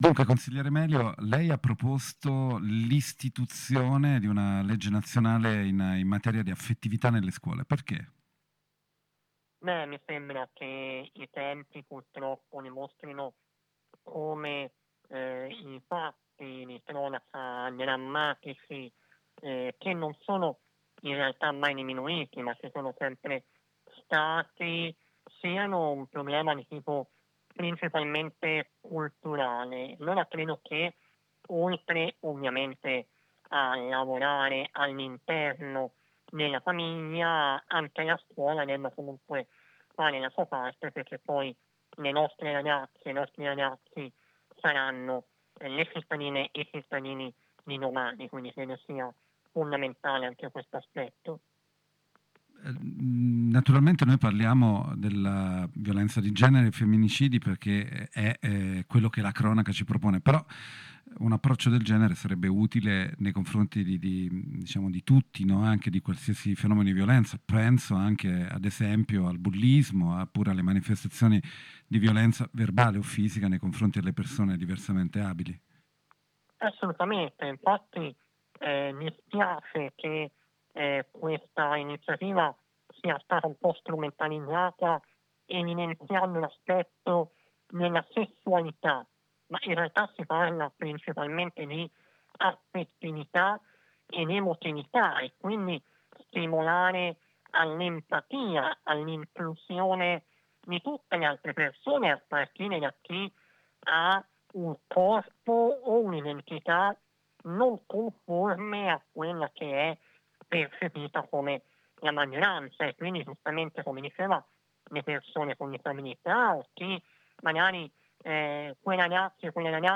Intervista con il consigliere regionale toscano del partito Democratico, Jacopo Melio